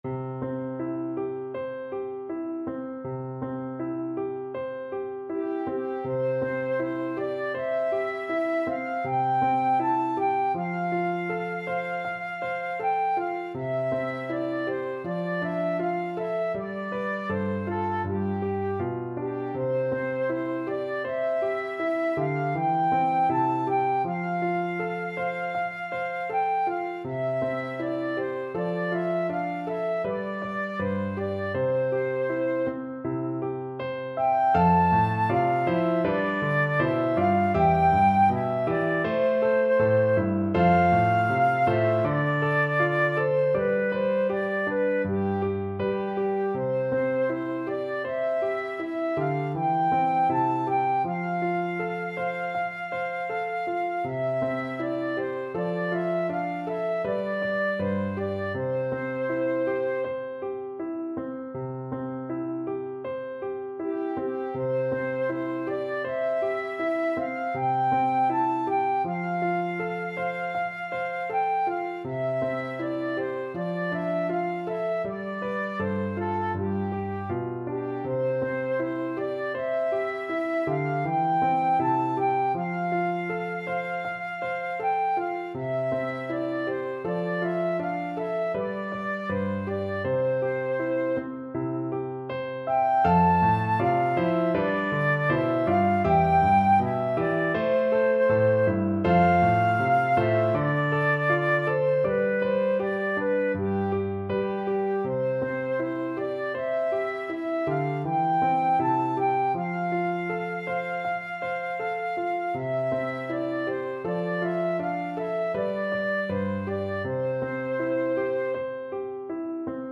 Flute version
Andante
2/4 (View more 2/4 Music)
Traditional (View more Traditional Flute Music)